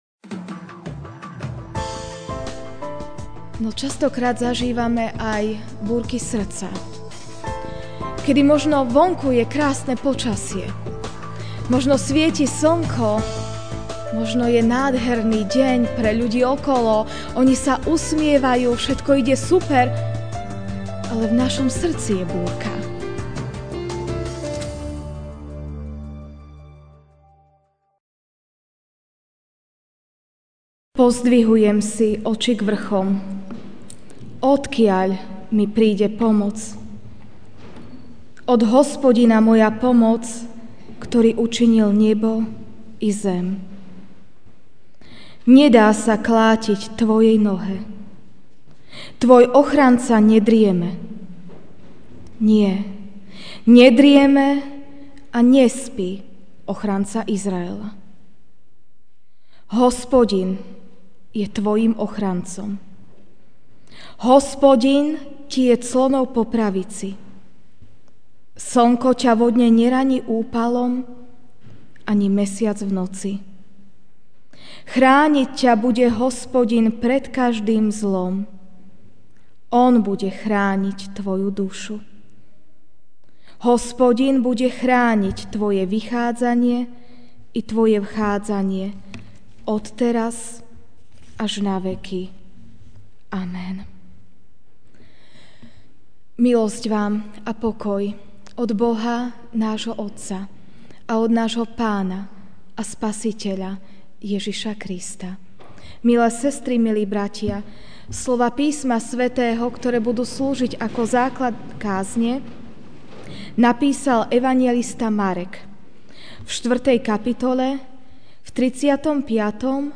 Ranná kázeň: Moc Pánova pri nás (Marek 4, 35-41) V ten istý deň, keď sa zvečerilo, povedal: Prejdime na druhú stranu!